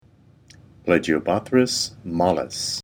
Pronunciation/Pronunciación:
Pla-gi-o-bó-thrys  mól-lis